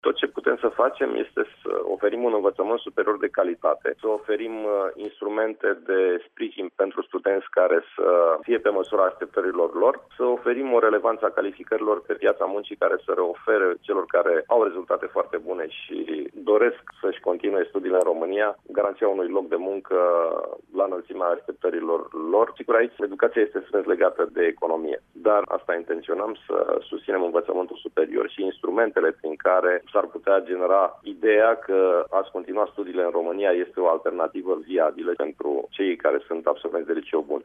Ministrul Sorin Câmpeanu a subliniat, astăzi, la Radio România Actualităţi, că acestor tineri trebuie să li se ofere calificările necesare pentru a obţine locul de muncă pe care şi-l doresc.